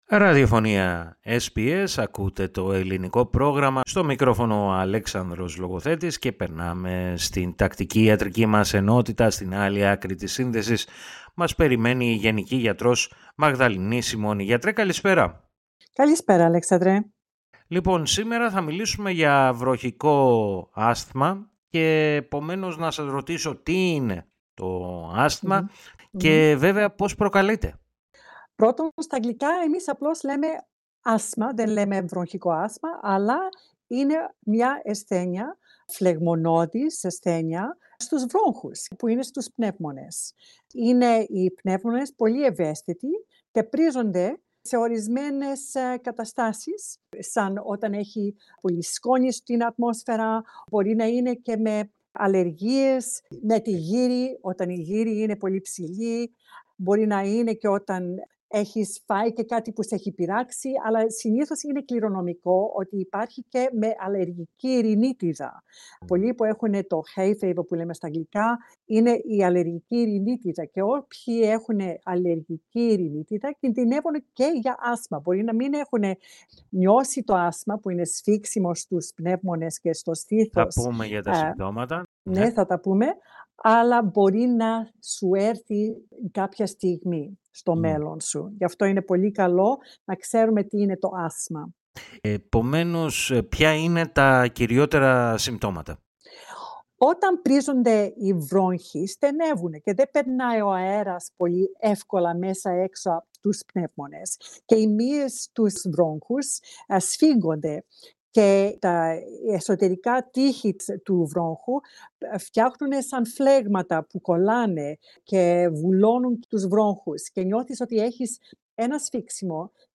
Η γενική γιατρός